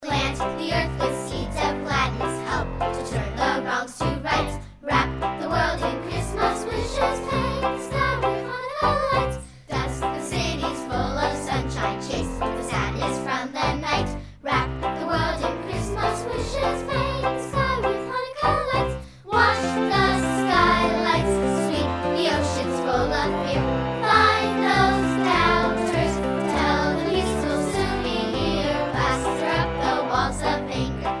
▪ The full-length music track with vocals.